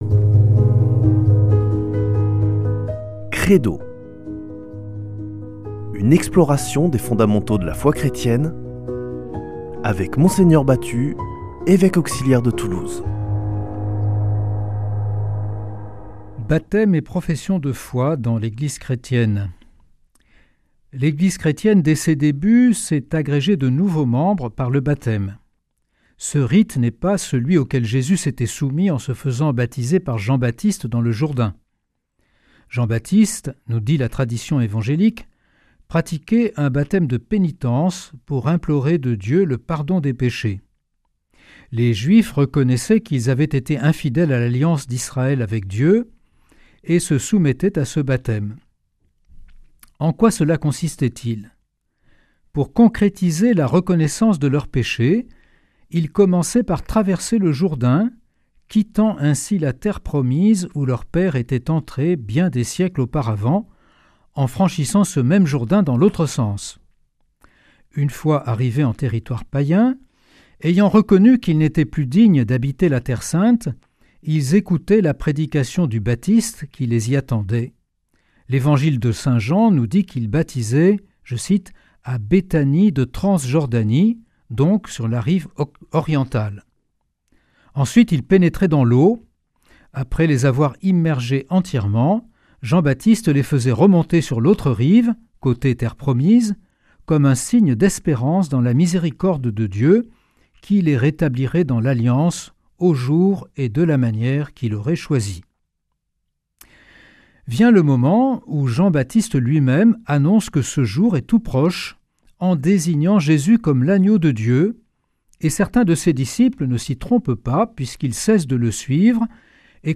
Partager Copier ce code (Ctrl+C) pour l'intégrer dans votre page : Commander sur CD Une émission présentée par Mgr Jean-Pierre Batut Evêque auxiliaire de Toulouse Voir la grille des programmes Nous contacter Réagir à cette émission Cliquez ici Qui êtes-vous ?